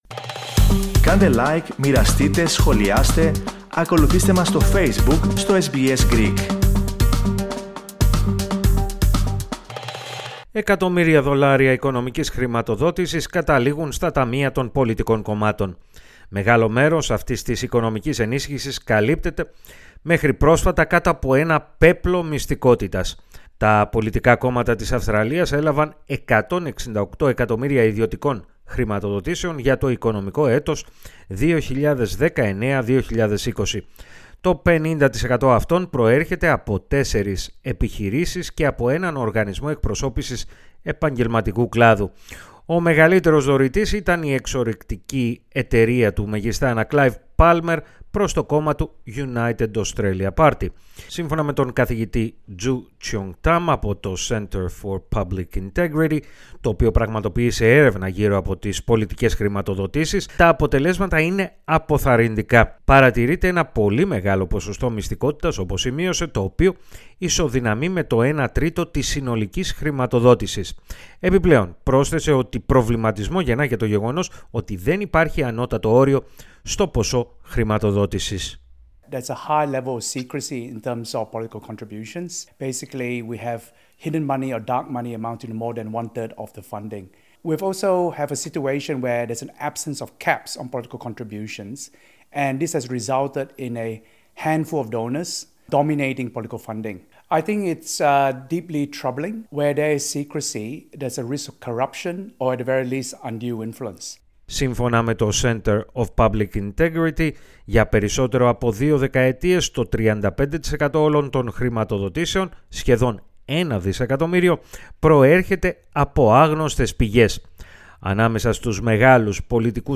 Περισσότερα ακούστε στην αναφορά